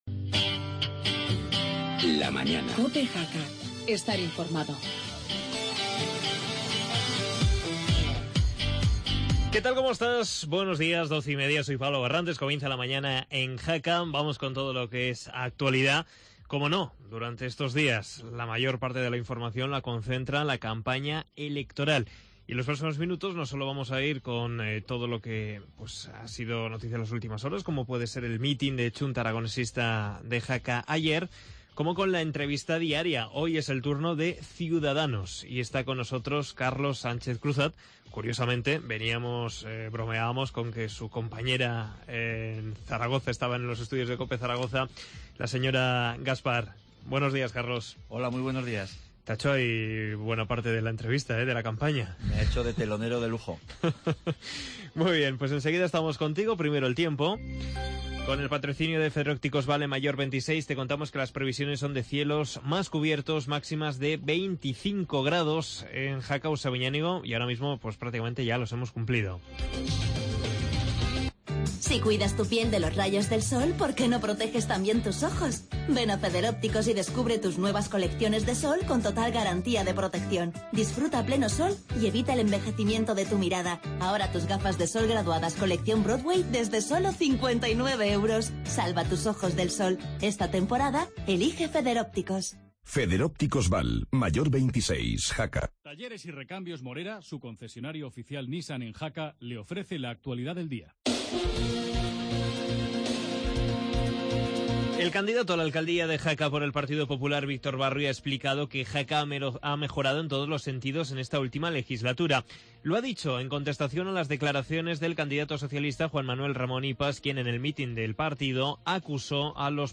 Actualidad con especial atención a la campaña electoral, entrevista